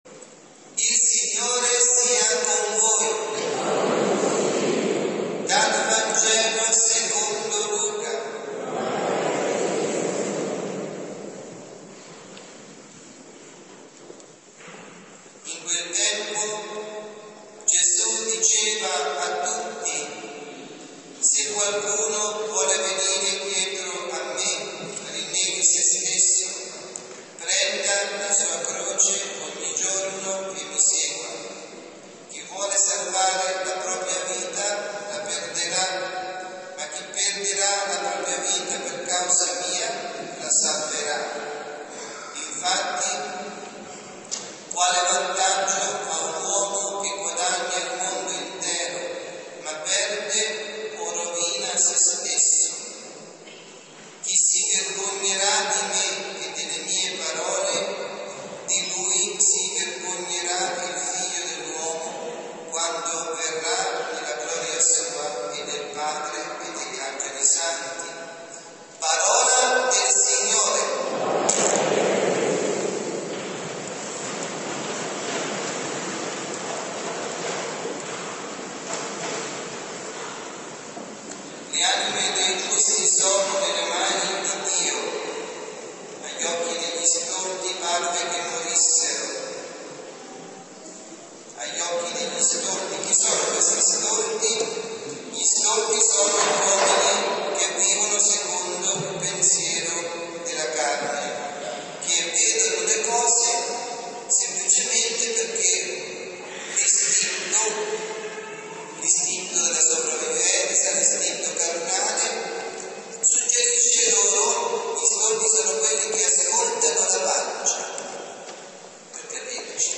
Cattedrale di San Catervo di Tolentino – Omelia